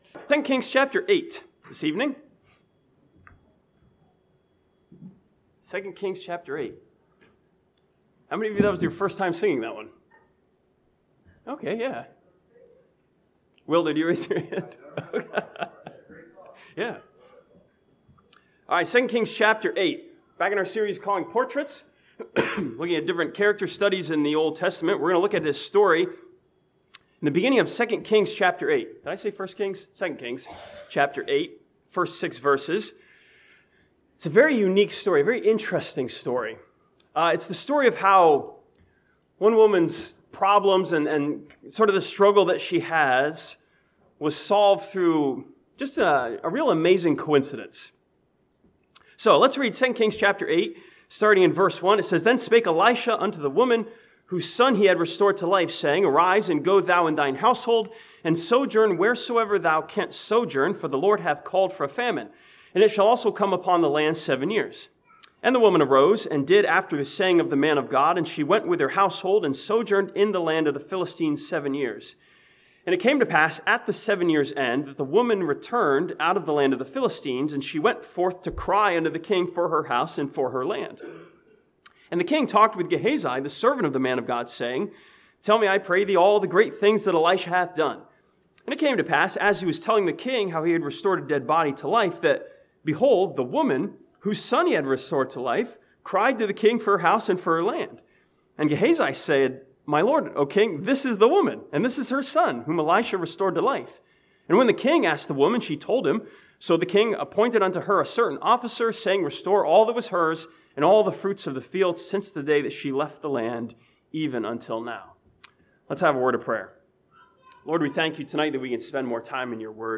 This sermon from 2 Kings chapter 8 looks at an interesting story which shows that a coincidence is God in control.